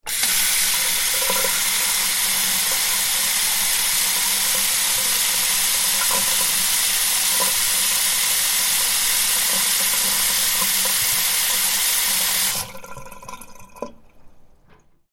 دانلود صدای شیر آب از ساعد نیوز با لینک مستقیم و کیفیت بالا
جلوه های صوتی